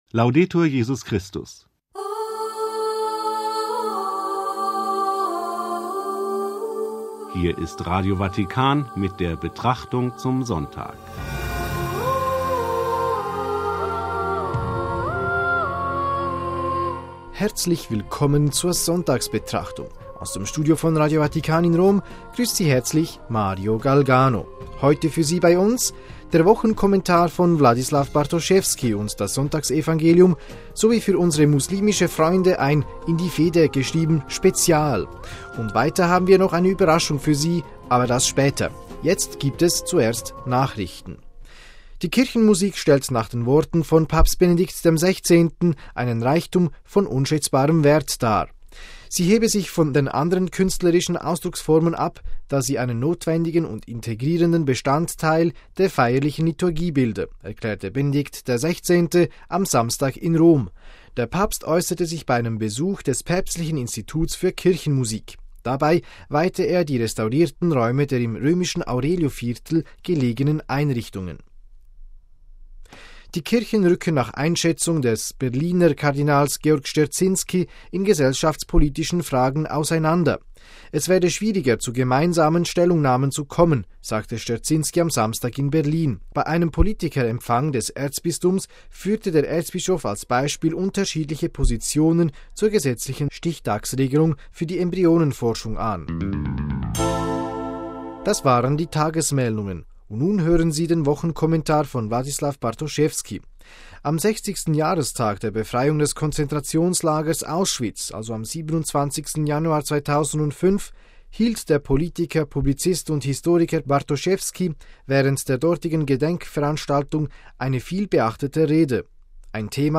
Das Sonntagsevangelium